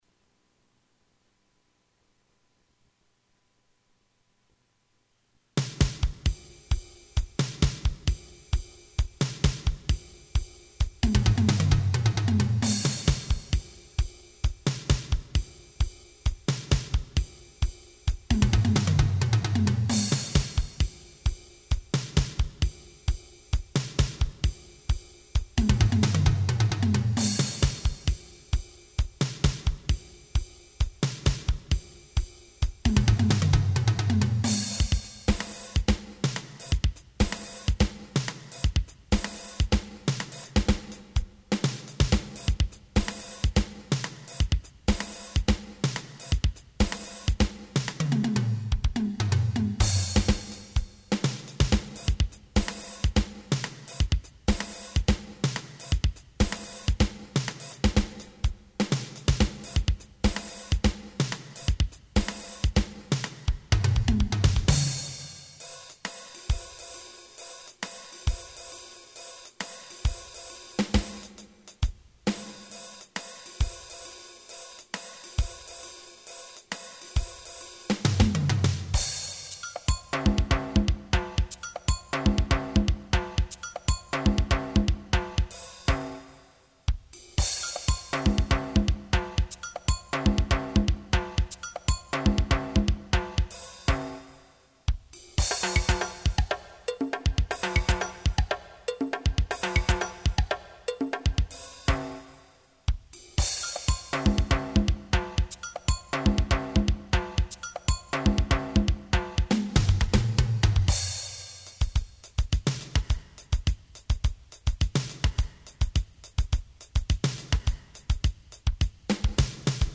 I selected a few at random; Rock, Funk, Swing, Latin Funk, Disco, Pop and Tango patterns. MP3 is a recording of the Roland SC-55 Standard drum kit.
DrumPatterns.mp3